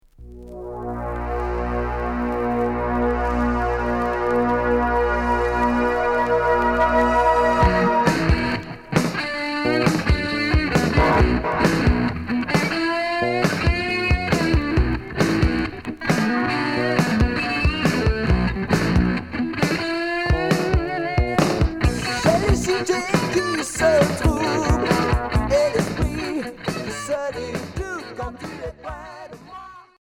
Rock Premier 45t retour à l'accueil